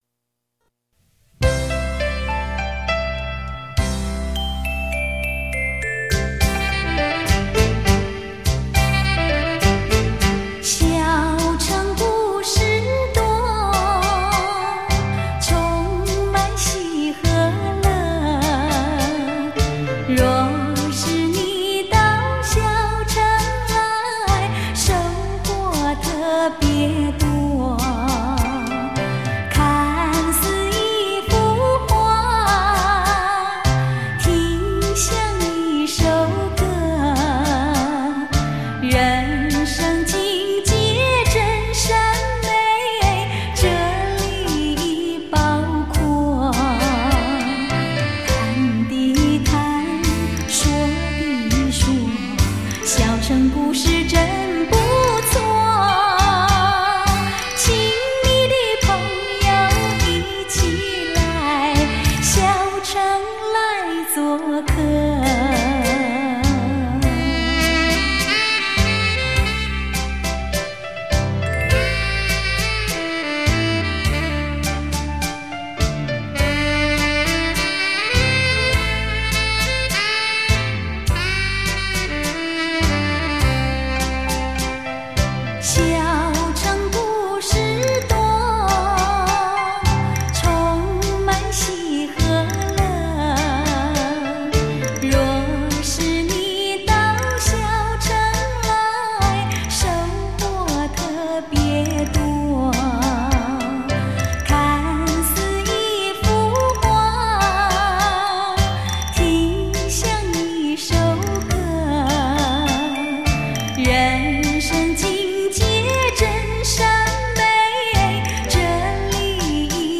探戈